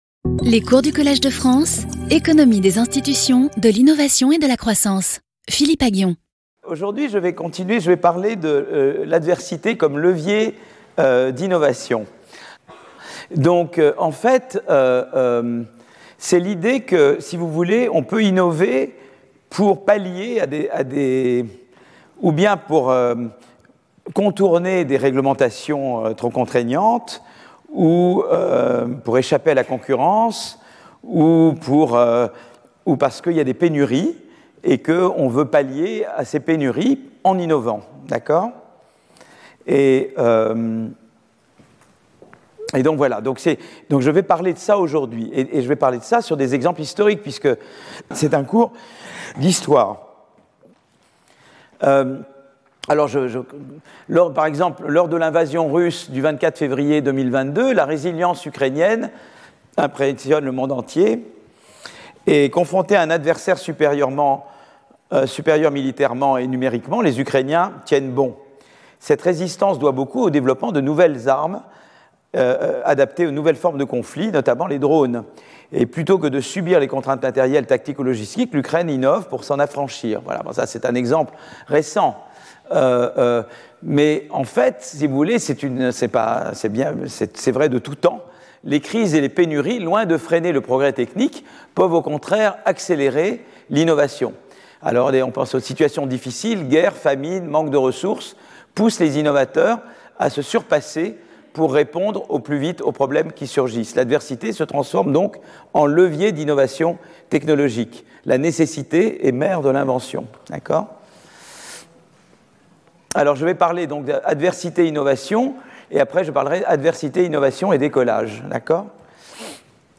Philippe Aghion Professeur du Collège de France
Cours